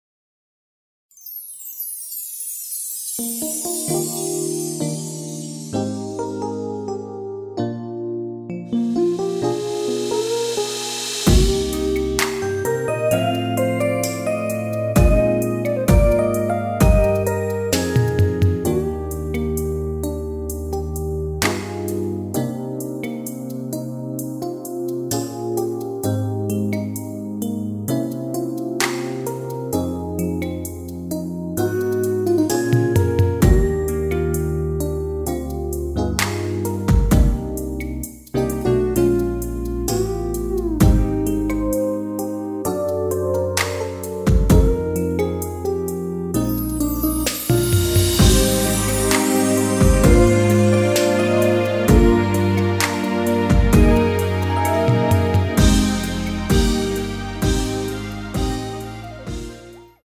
Eb
앞부분30초, 뒷부분30초씩 편집해서 올려 드리고 있습니다.